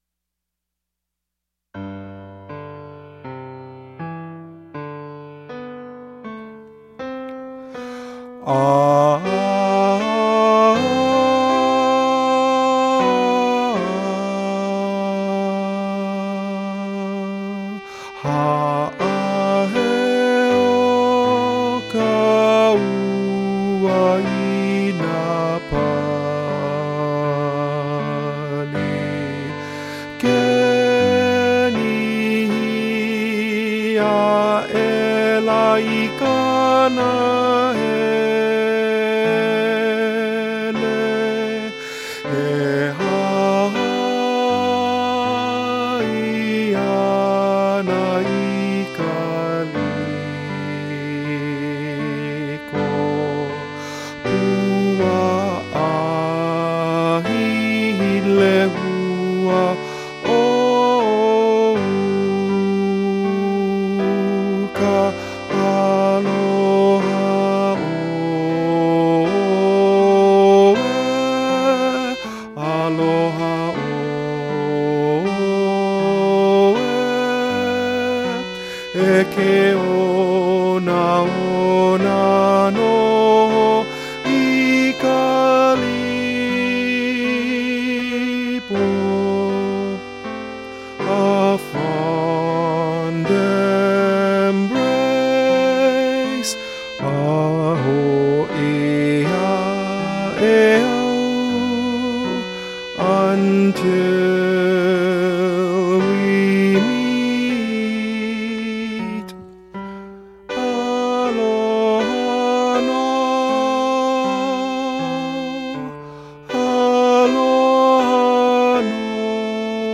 Soprano   Instrumental | Downloadable   Voice | Downloadable